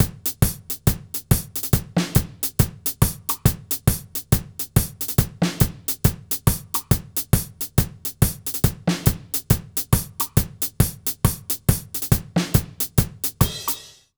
British REGGAE Loop 144BPM.wav